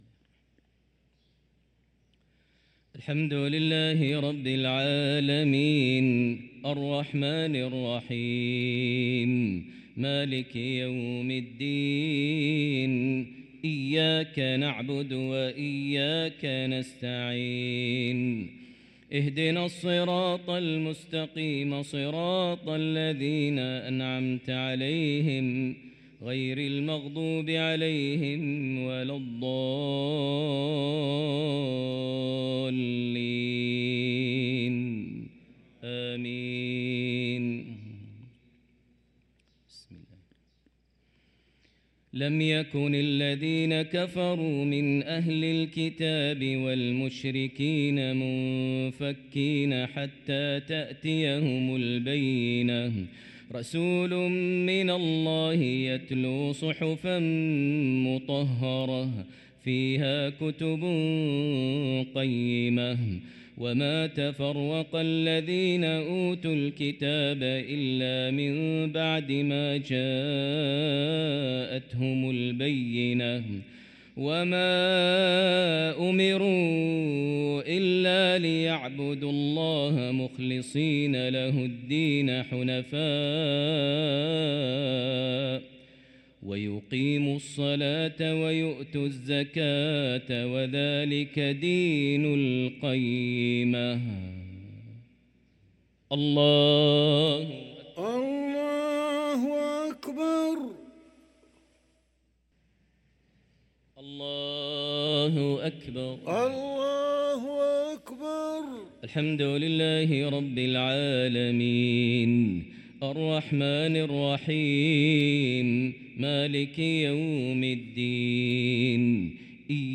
صلاة المغرب للقارئ ماهر المعيقلي 16 ربيع الآخر 1445 هـ
تِلَاوَات الْحَرَمَيْن .